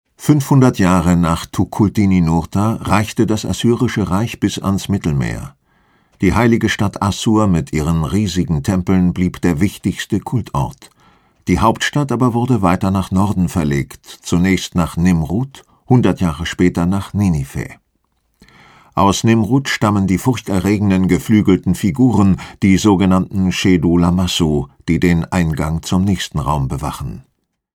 Die mp3s sind im Stereo-Format und haben